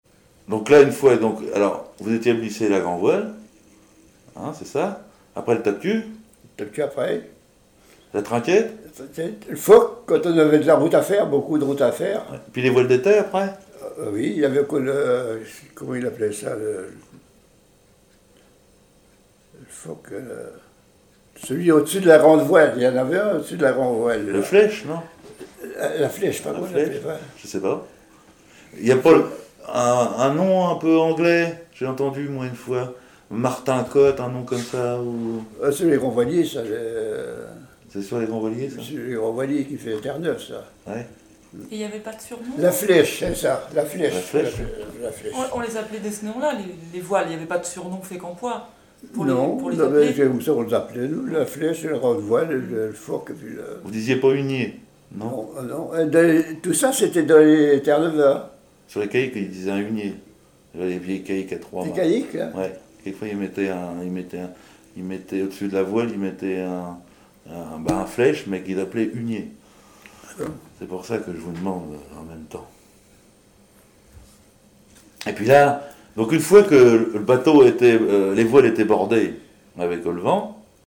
Témoignages sur la pêche sur les voiliers
Catégorie Témoignage